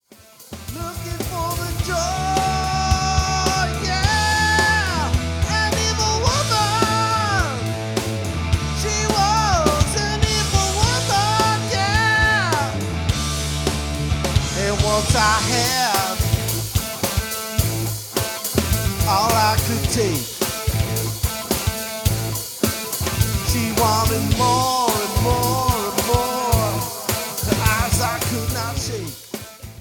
Vocals, Bass
Drums, Guitars